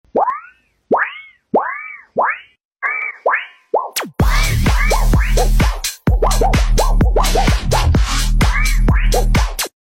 Goofy Ahhh Metal Sheet Sound sound effects free download